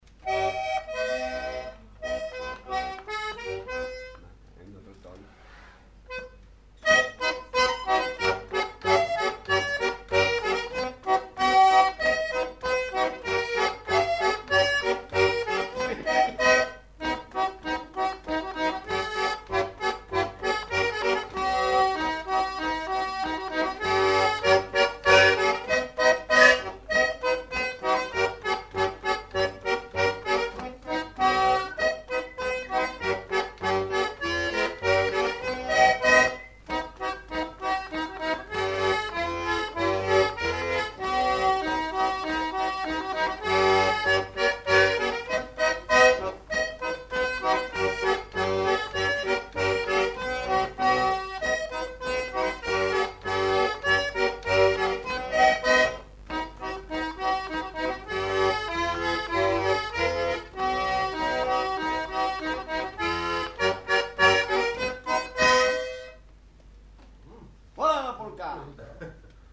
Lieu : Pyrénées-Atlantiques
Genre : morceau instrumental
Instrument de musique : accordéon diatonique
Danse : scottish
Notes consultables : L'interpr. annonce une polka.